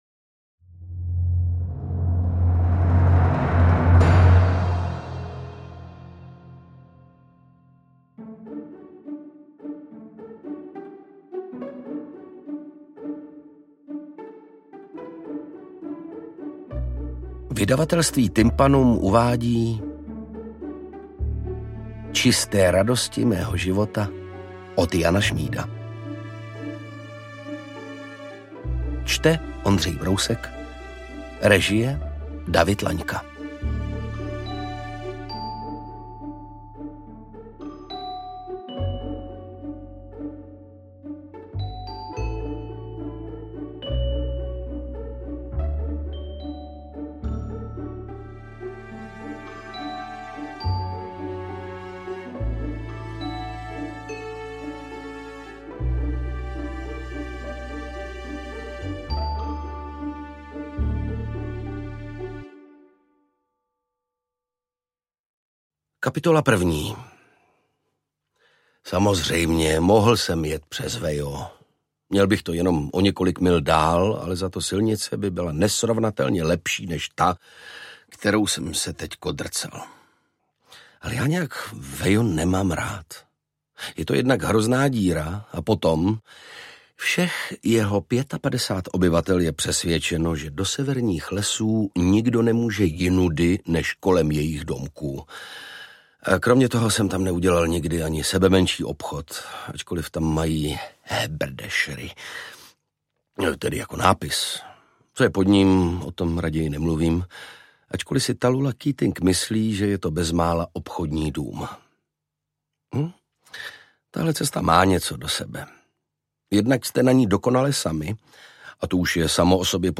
Interpret:  Ondřej Brousek
AudioKniha ke stažení, 27 x mp3, délka 10 hod. 39 min., velikost 584,9 MB, česky